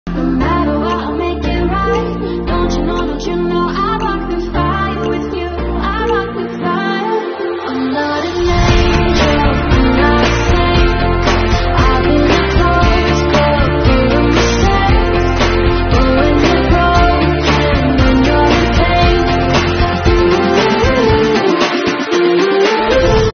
11月28日，“趣吉林?滑呗”新雪季开板大会暨2020“白山之冬”冰雪旅游季大幕拉开，长白山国际度假区内欢声笑语、激情四射，开启了一场激情迸发的冰雪盛宴。
小编带您云逛现场~